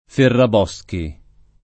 [ ferrab 0S ki ]